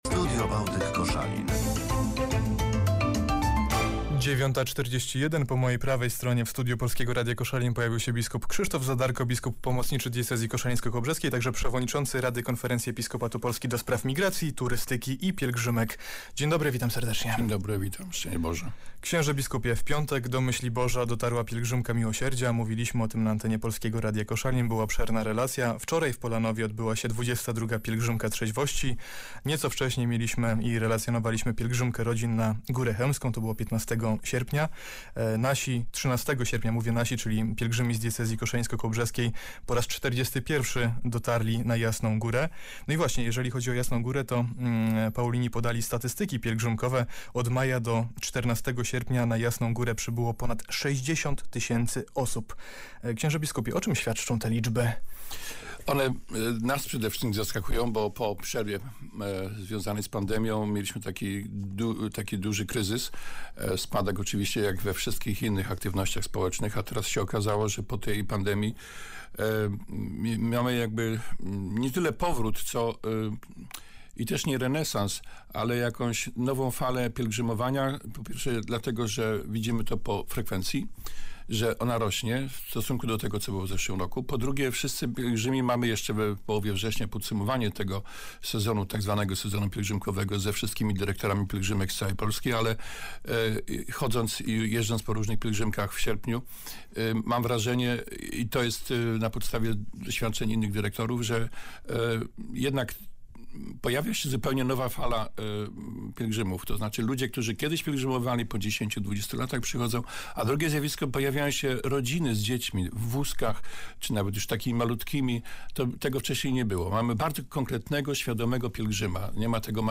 28 sierpnia gościem audycji "Studio Bałtyk" w Polskim Radiu Koszalin był bp Krzysztof Zadarko - biskup pomocniczy diecezji koszalińsko-kołobrzeskiej, przewodniczący rady KEP ds. Migracji, Turystyki i Pielgrzymek.
Bp Krzysztof Zadarko na antenie Polskiego Radia Koszalin